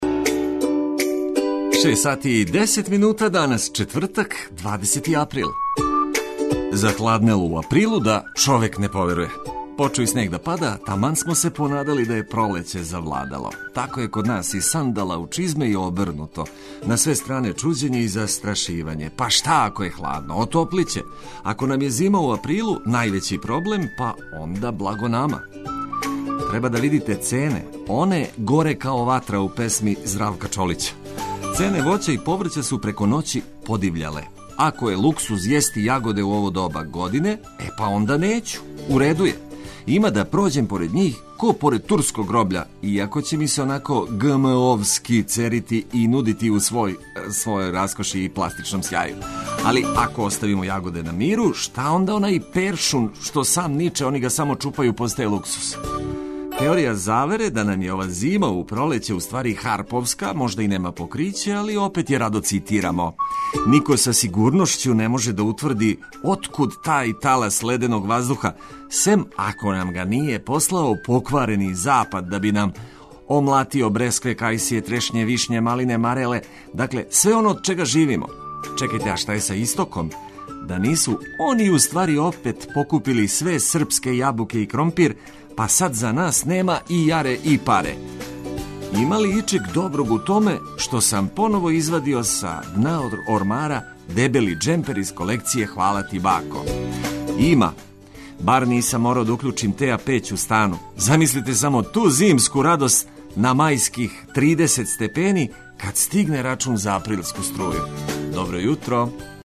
Упркос захлађењу кад му време није, наша препорука је да за себе на време обезбедите пријатно јутарње расањивање уз корисне информације и ведру музику.